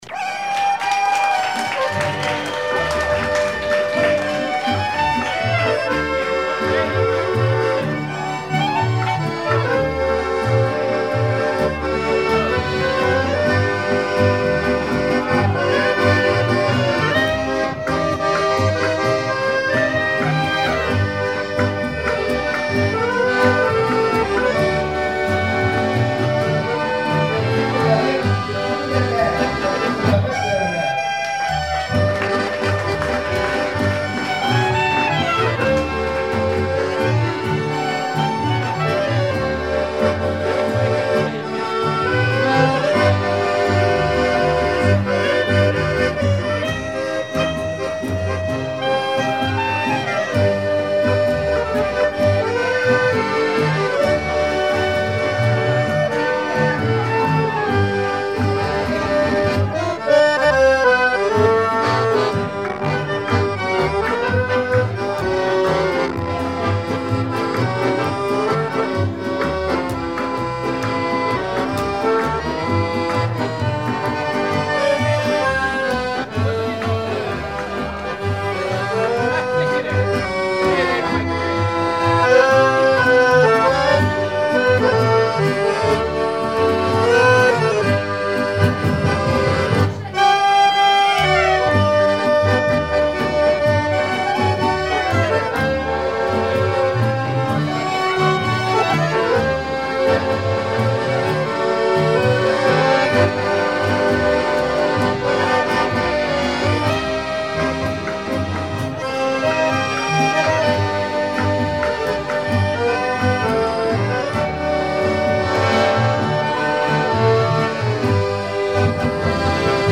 Informateur(s) Horo orchestre
Pièce musicale inédite